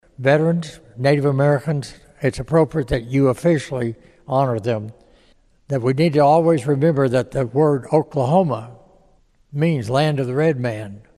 CLICK HERE to listen to commentary from former Governor George Nigh.
He worked in that building for over 30 years and was back to speak to the Senate on a resolution honoring Native American Veterans and Veterans at large. Nigh also complimented the lawmakers on the renovations that have been made to the capital building.